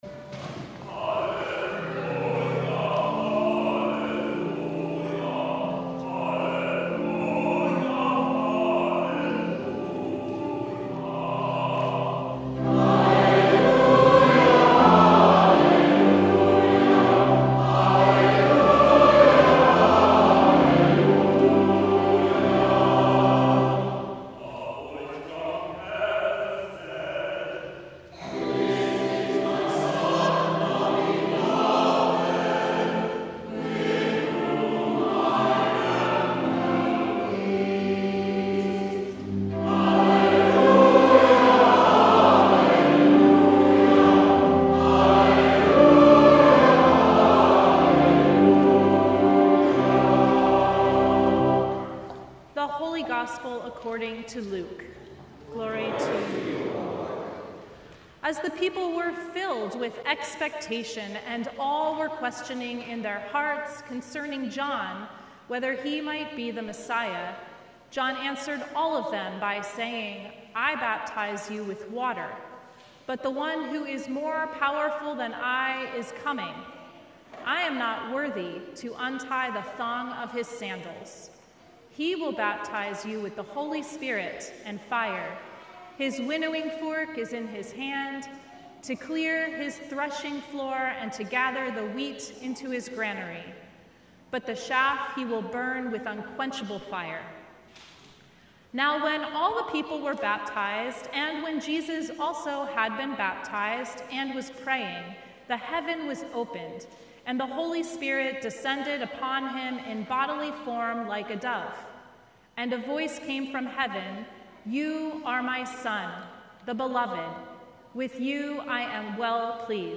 Sermon from Baptism of Our Lord 2025
Sermon Notes